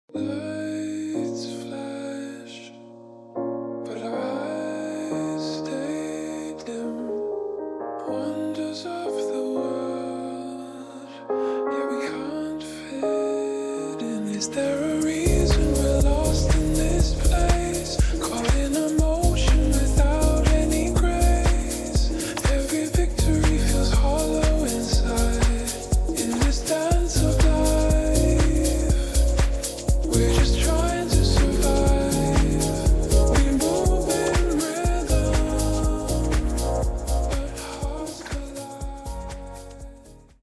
R & B